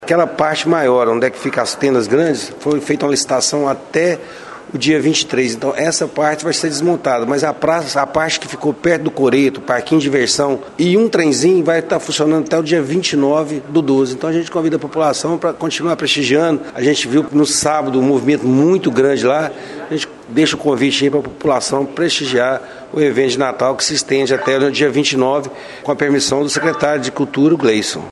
E a novidade é que algumas atrações seguem funcionando até o fim do mês, como conta o vereador, Marcílio Souza, que também é proprietário de uma das barracas montadas na praça Torquato de Almeida.